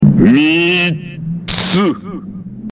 Another oddity is that all of the dialogue for the previous episode clips was re-recorded, and sounds different to before.